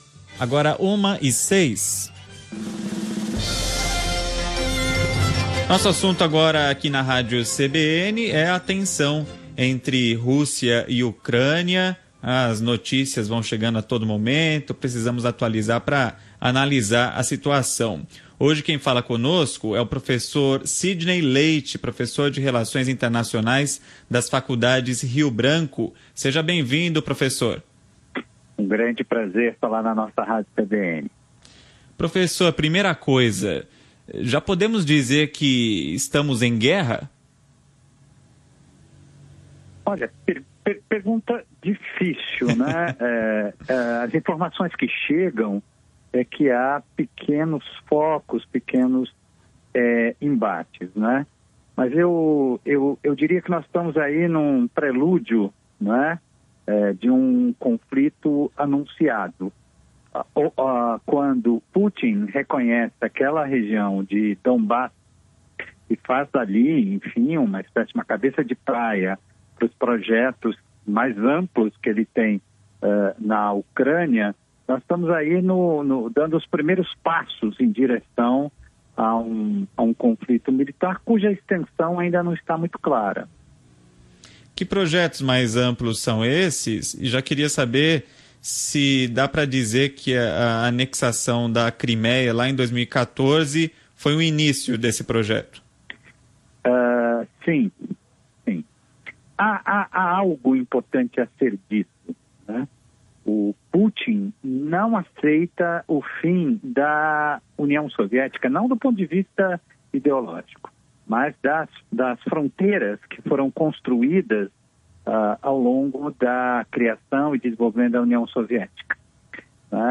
concedeu entrevista para a Rádio CBN